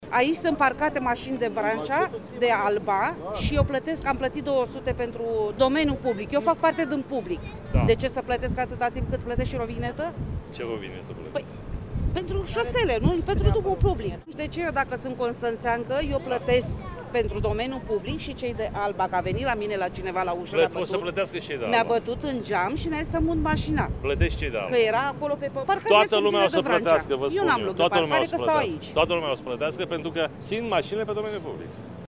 Declarația a fost făcută astăzi de primarul Vergil Chițac, la o întâlnire cu cetățenii domiciliați în zona adicentă străzii Corbului.